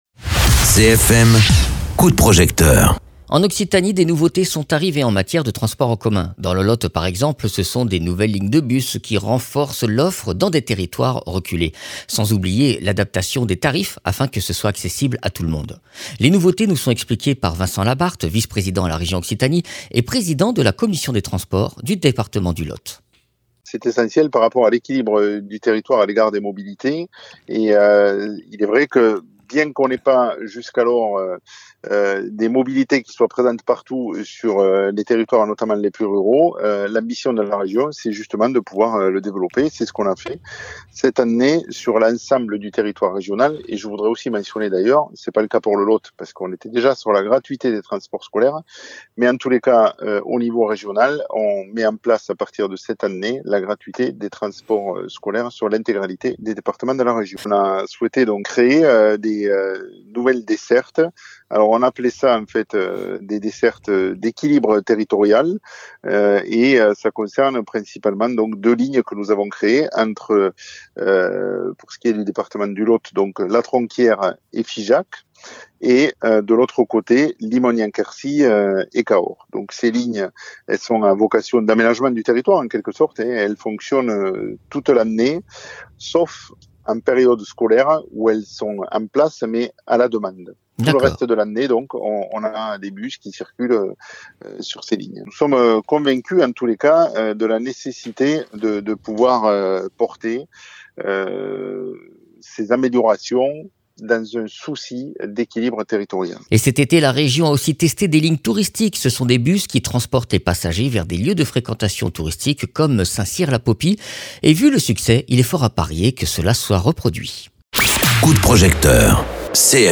Interviews
Invité(s) : Vincent Labarthe, Président de la commission des transports du Lot